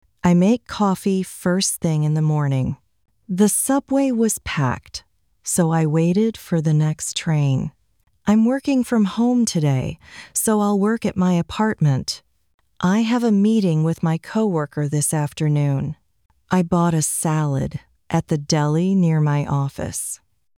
（シャドーイングに最適）